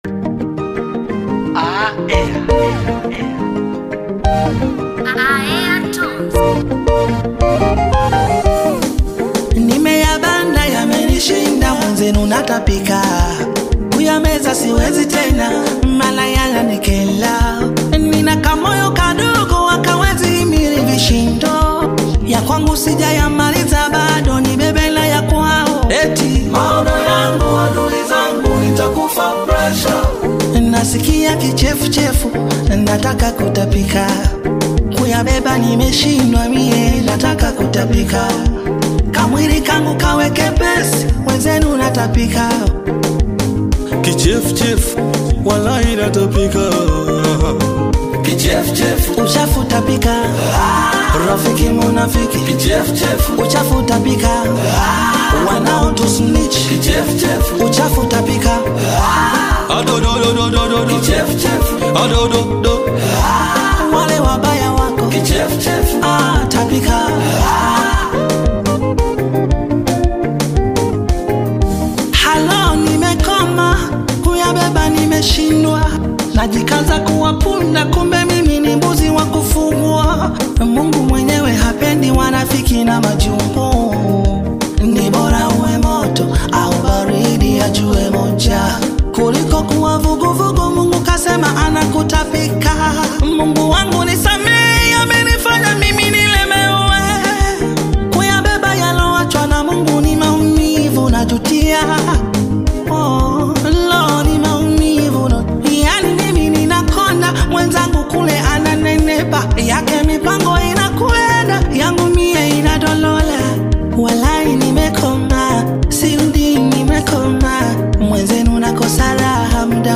Tanzanian Gospel artist, singer, and songwriter